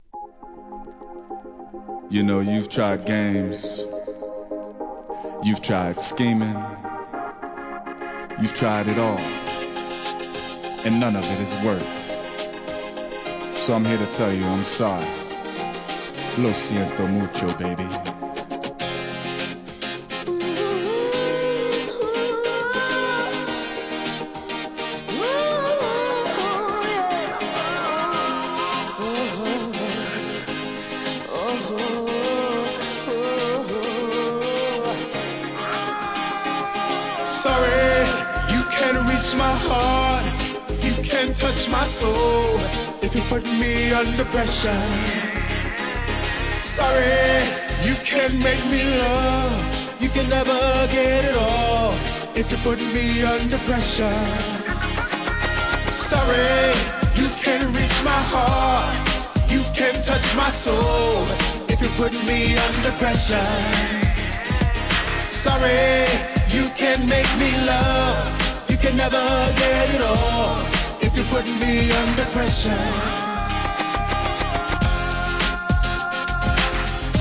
HIP HOP/R&B
ヨーロッパ産RAP! 切ないギターの哀愁サウンドで歌い上げるフックが印象的です!